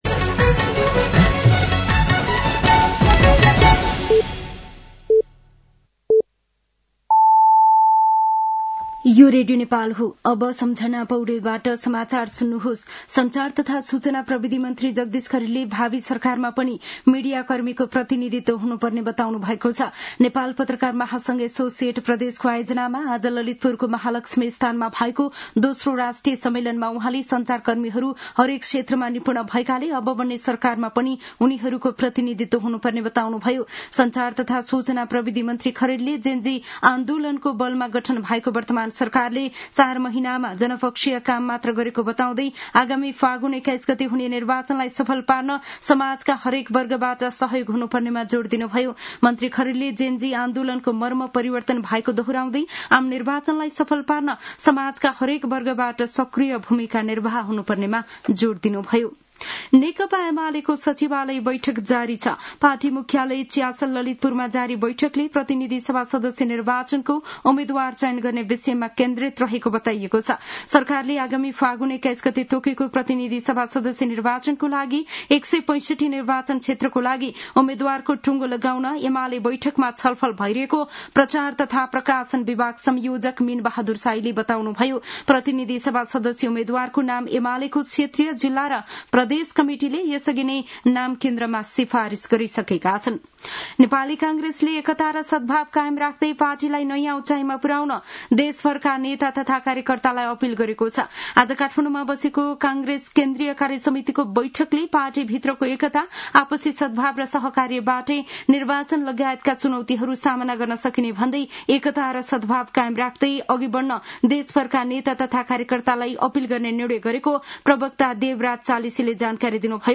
साँझ ५ बजेको नेपाली समाचार : ३ माघ , २०८२
5.-pm-nepali-news-1-6.mp3